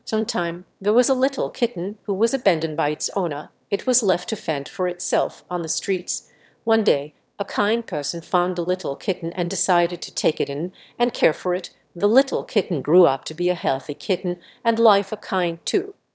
story0_CopyMachine_1.wav